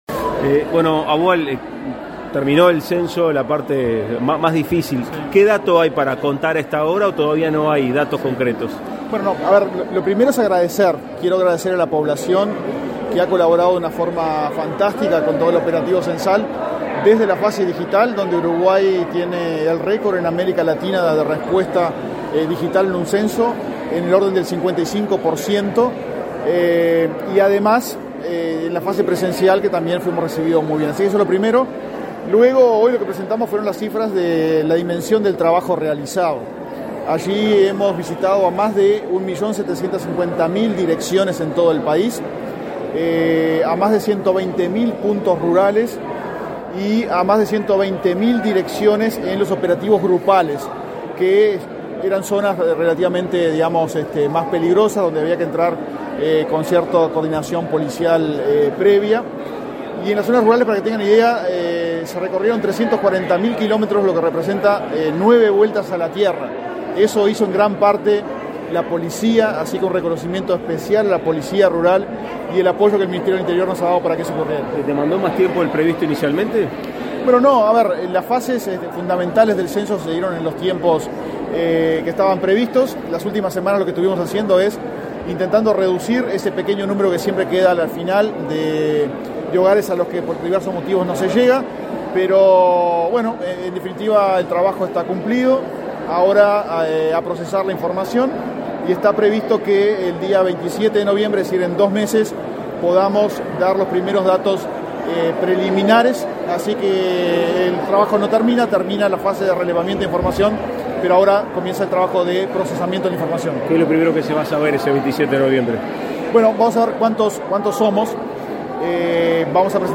Declaraciones a la prensa del director del INE, Diego Aboal
Declaraciones a la prensa del director del INE, Diego Aboal 29/09/2023 Compartir Facebook X Copiar enlace WhatsApp LinkedIn Tras participar en la conferencia por el cierre del Censo 2023, este 29 de setiembre, el presidente del Instituto Nacional de Estadísticas (INE), Diego Aboal, realizó declaraciones a la prensa.